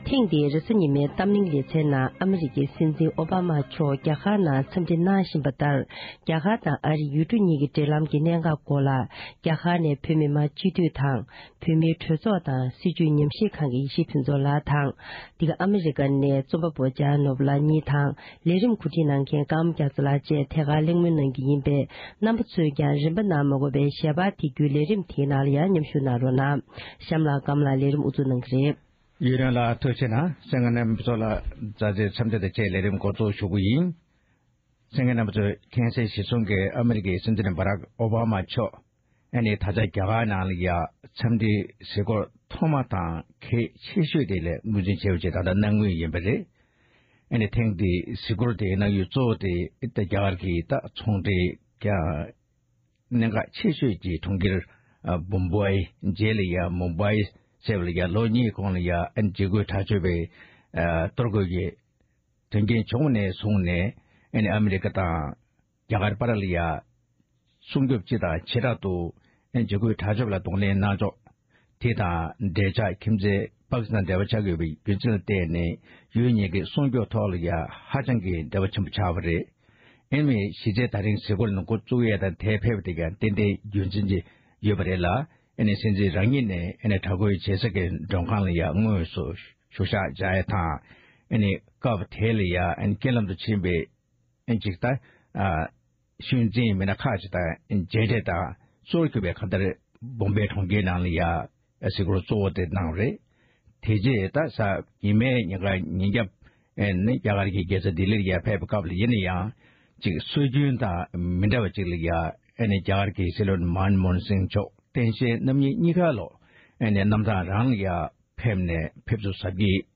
གླེང་མོལ་གནང་བར་གསན་རོགས༎